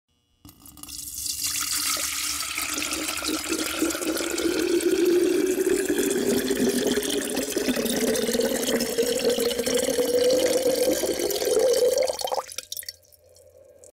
Звуки чайника
Звук наполнения чайника водой